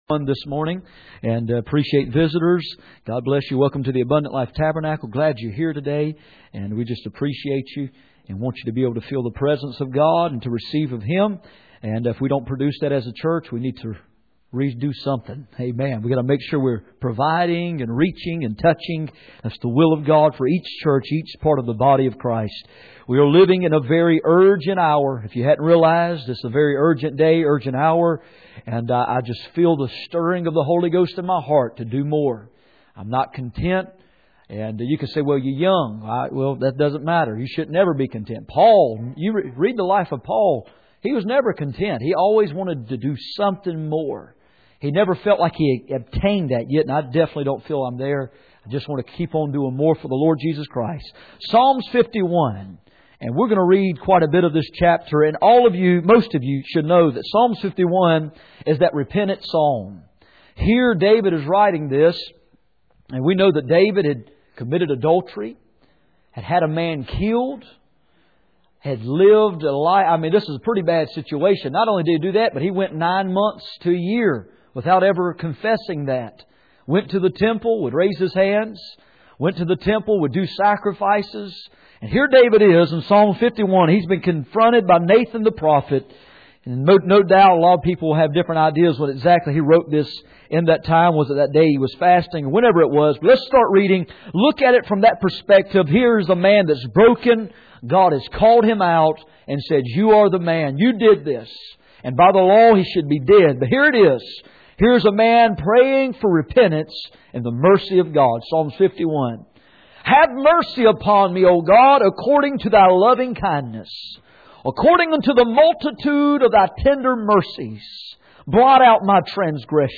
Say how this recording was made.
Passage: Psalm 51:1-14 Service Type: Sunday Morning